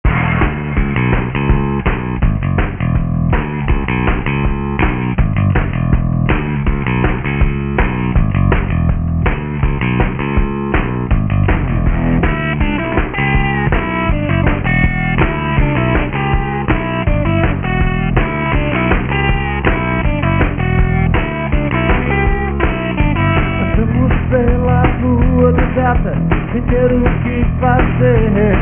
roqueiro do barulho